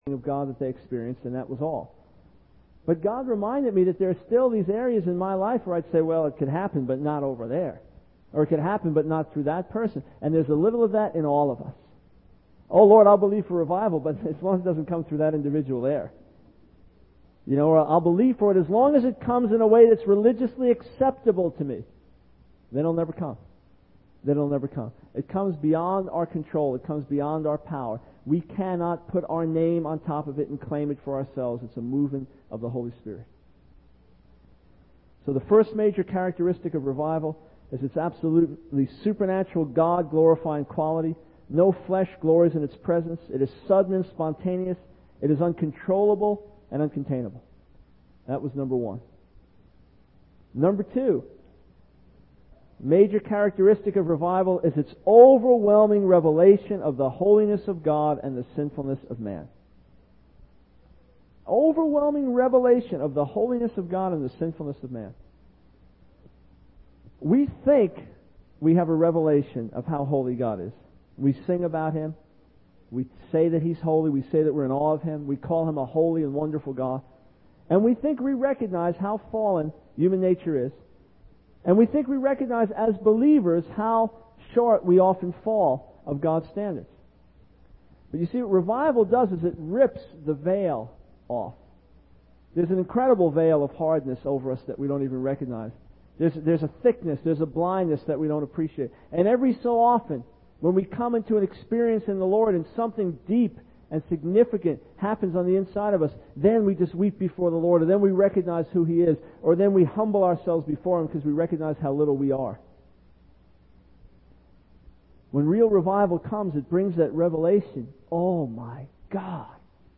In this sermon, the preacher describes the experience of revival and its impact on individuals and society.